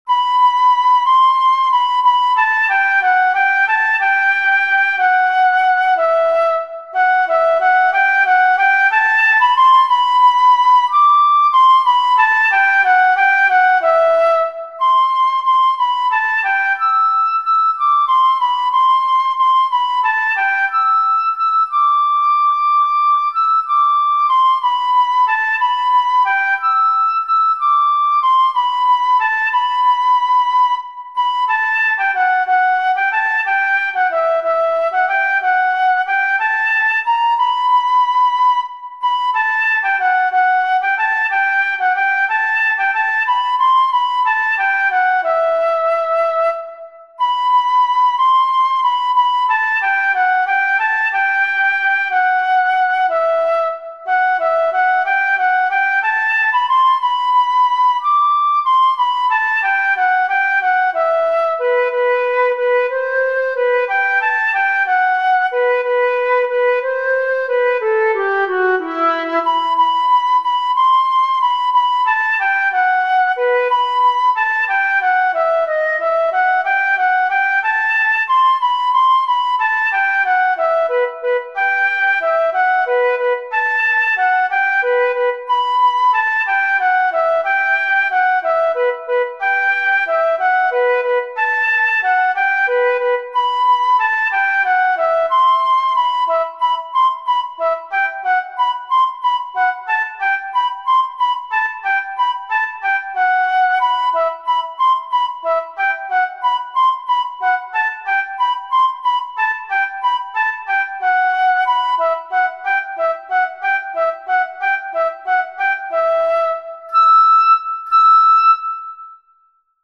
Allegretto
Ütemmutató: 6/8 Tempo: 92 bpm
Előadói apparátus: szóló fuvola